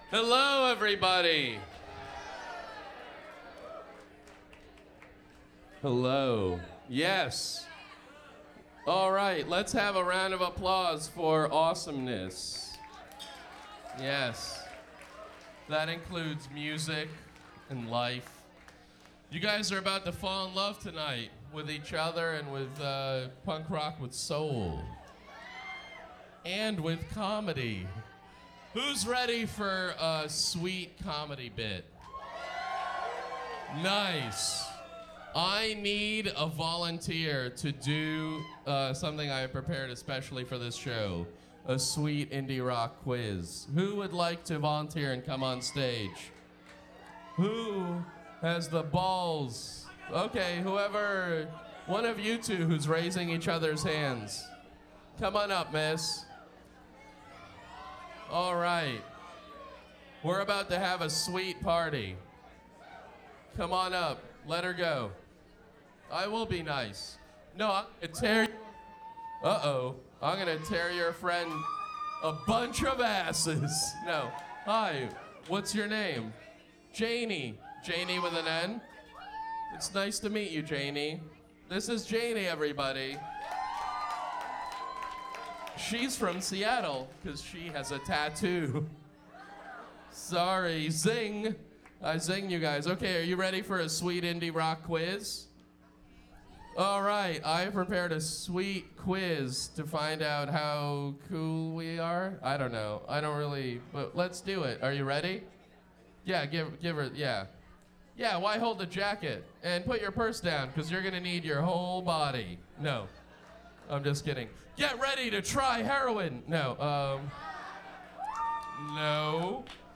His second bit was a makeshift quiz show where he made fun of audience members who willingly participated in his shenanigans and good fun was had by all.
Technical Note: the clip from 2006-06-29 is just an AUD source and the clip from 2006-06-30 is an AUD/SBD matrix.
Eugene Mirman – 2006-06-30 Neumo’s – Seattle, WA